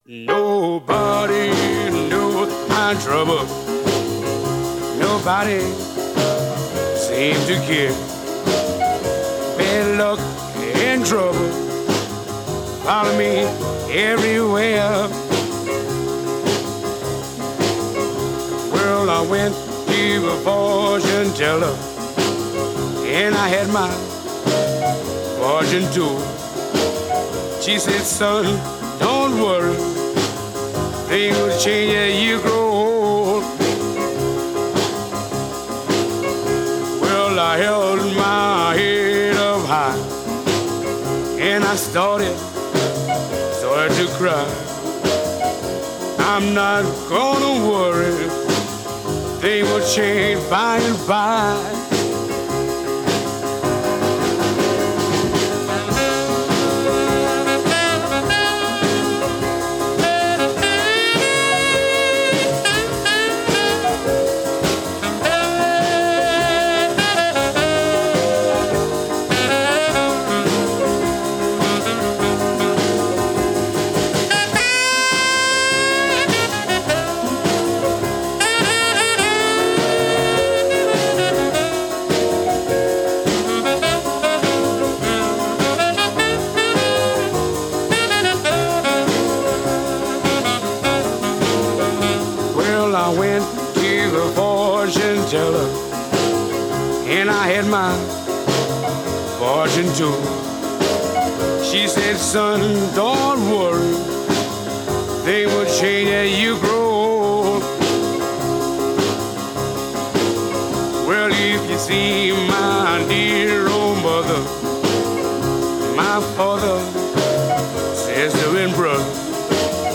Американский пианист